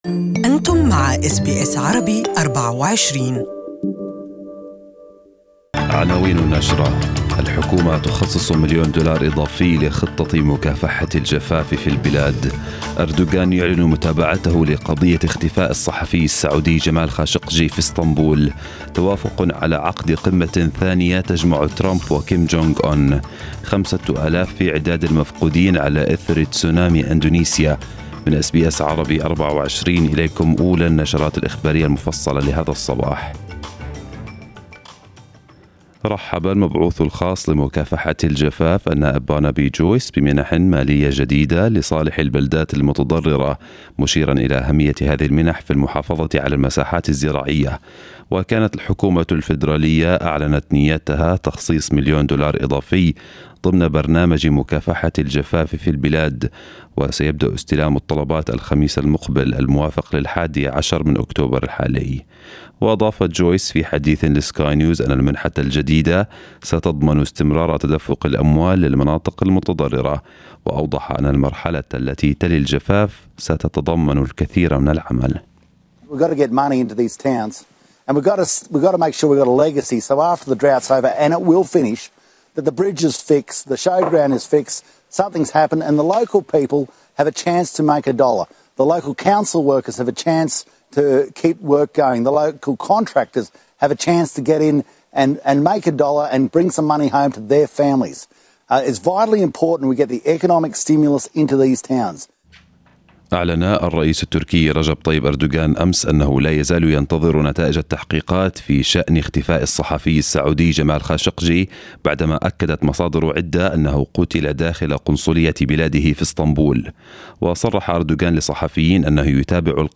News bulletin this morning